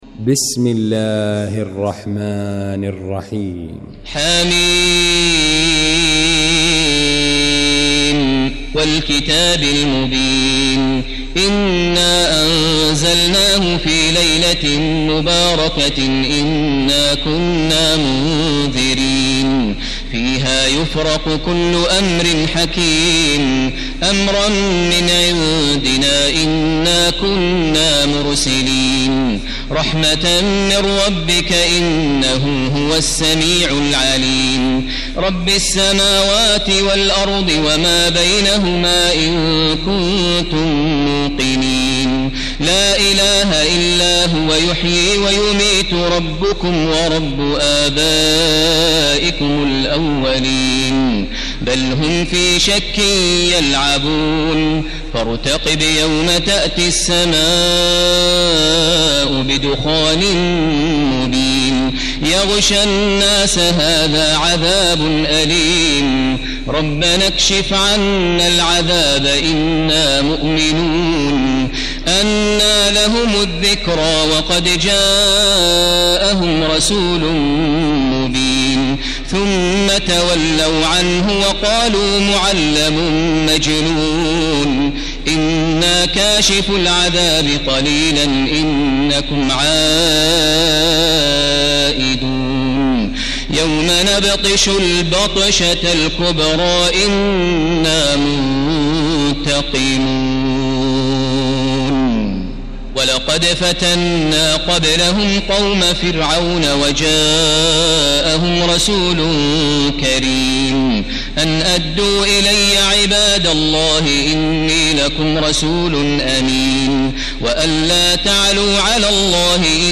المكان: المسجد الحرام الشيخ: فضيلة الشيخ ماهر المعيقلي فضيلة الشيخ ماهر المعيقلي الدخان